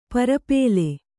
♪ parapēle